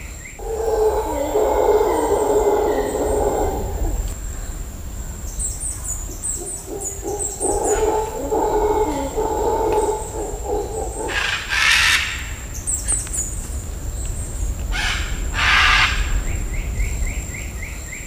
HowlerMonkey&Birds.ogg